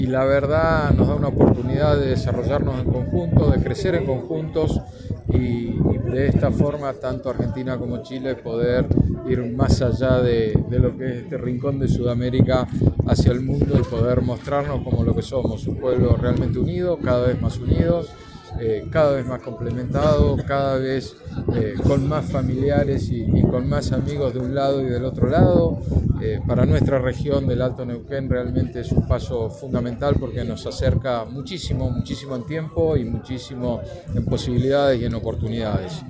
A su vez, Gustavo Fernández, ministro de Turismo de la provincia de Neuquén, sostuvo que este paso fronterizo genera oportunidades para ambos países.